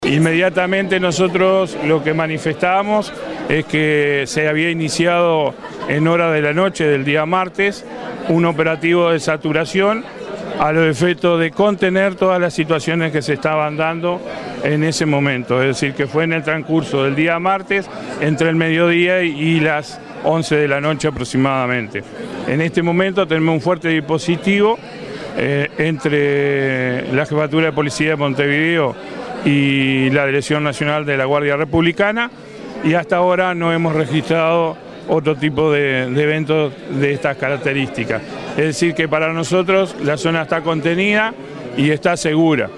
En rueda de prensa, el jefe de policía, Mario Layera dijo que la situación ya está controlada y agregó que los docentes tomaron la decisión de abandonar la escuela y la policía colaboró con esa evacuación.